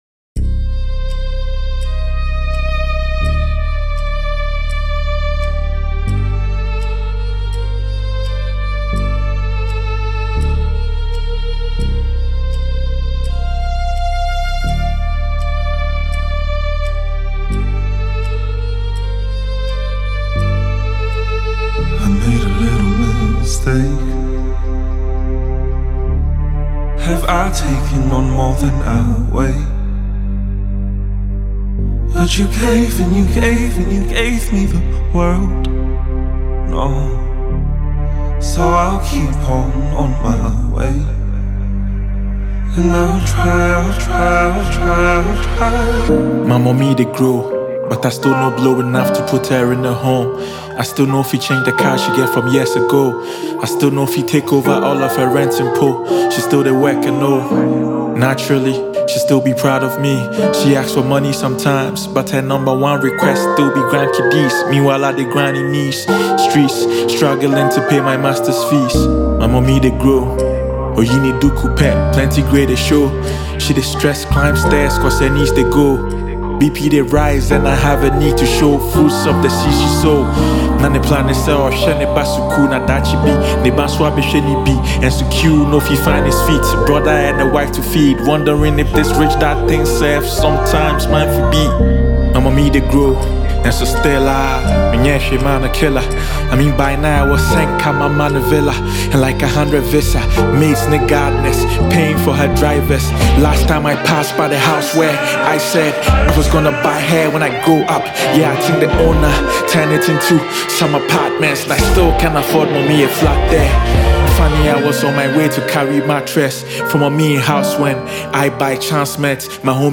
Ghanaian rapper